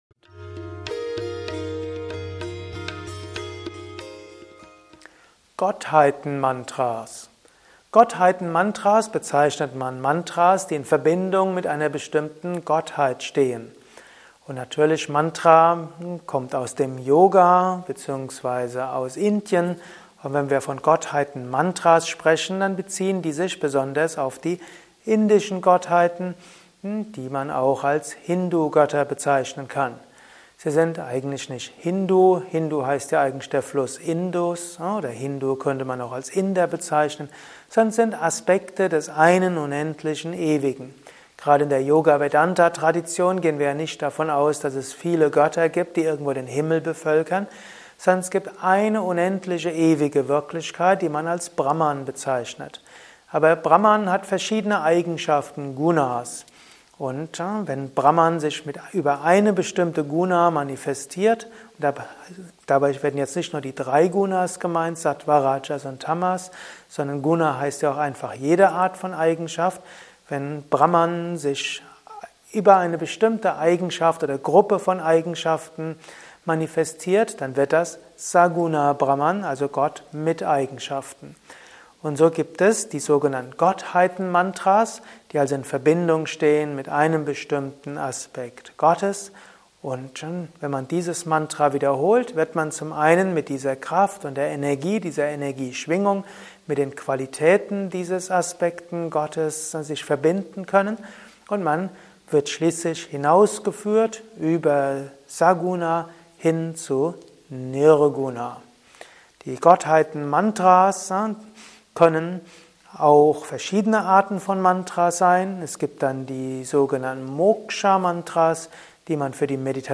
Mantra-Meditation-Podcast ist die Tonspur eines Mantra Videos.